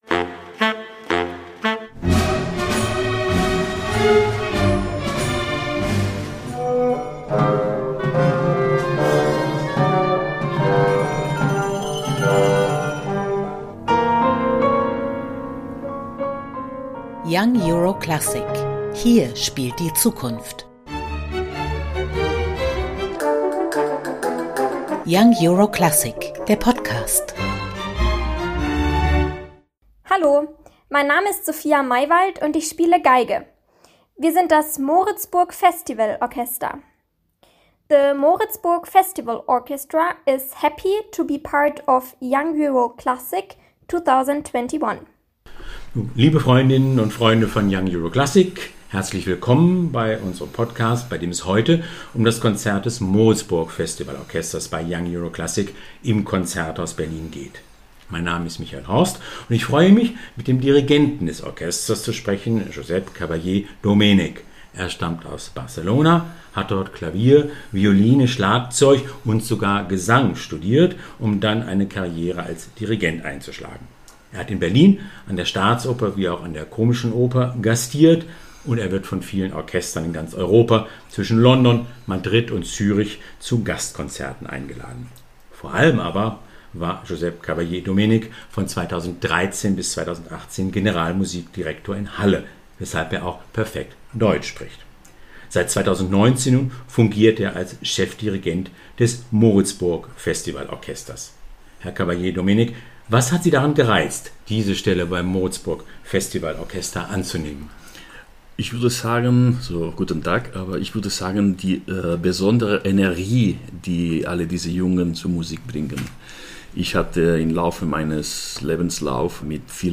Konzerteinführung